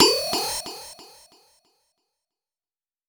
SouthSide Trap Transition (1).wav